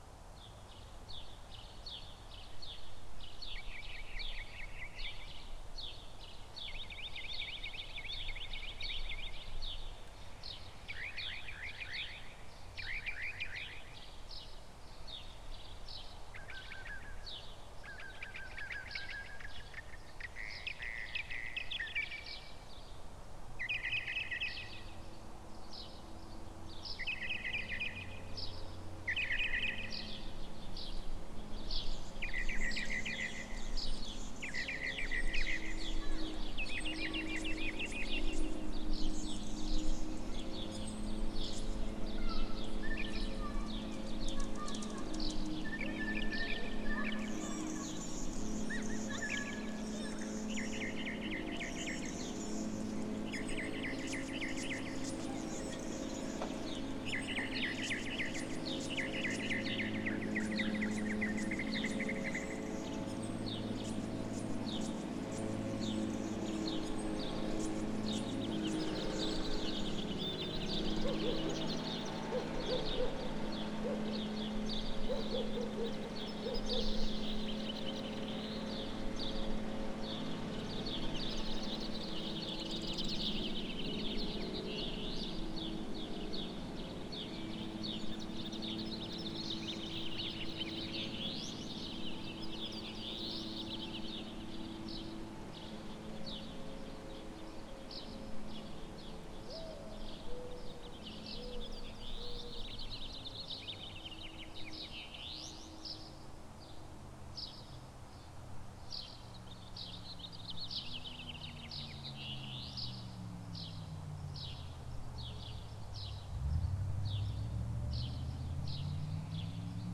Town or suburbs amb - spring, day
ambience america american annas-hummingbird arizona bird birds blooming sound effect free sound royalty free Nature